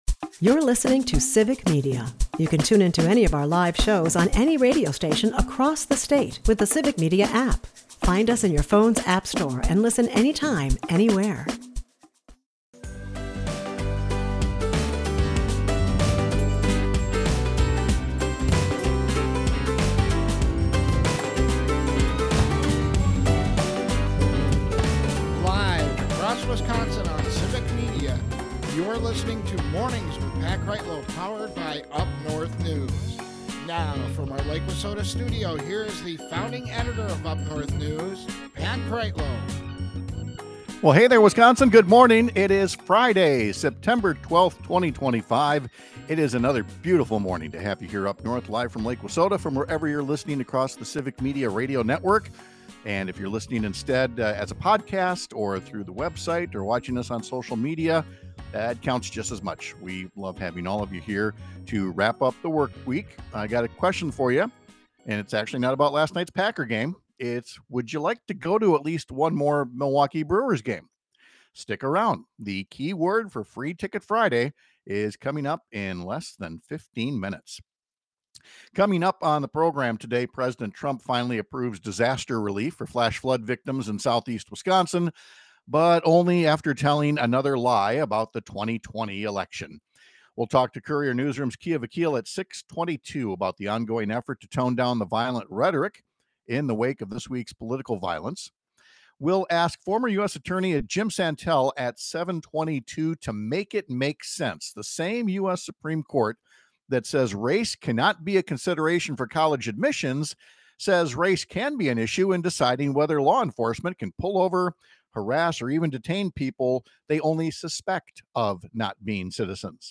They take calls from the listeners and read texts, as well who very happy to share their thoughts with the gang about Tuesday night.